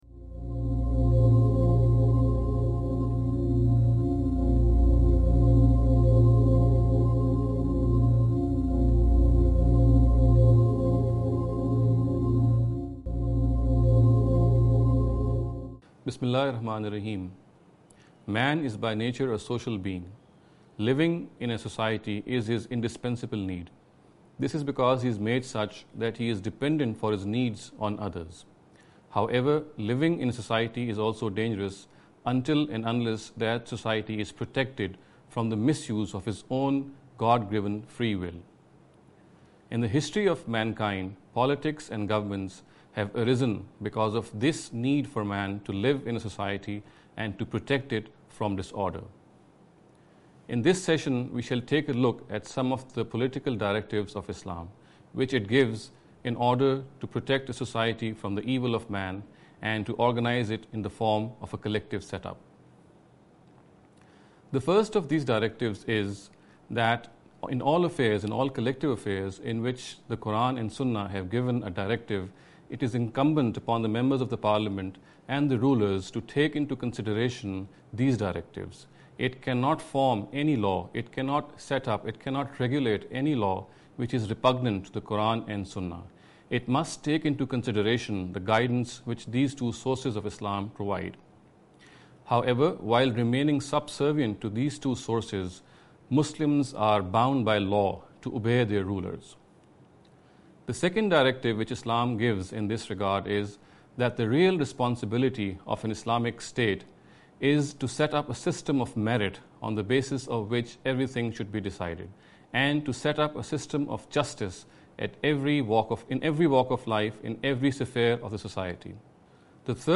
This lecture series will deal with some misconception regarding the Understanding The Qur’an.